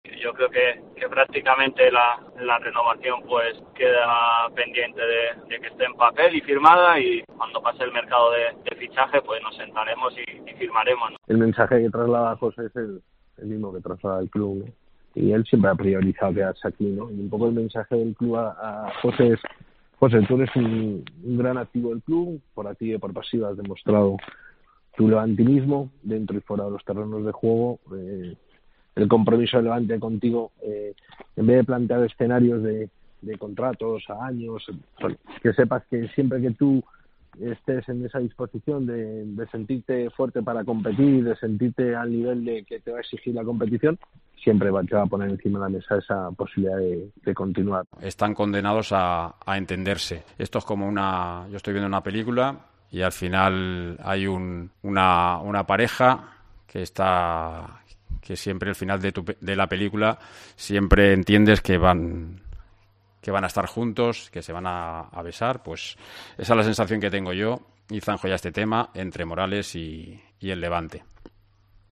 AUDIO. Las palabras de los protagonistas en el caso Morales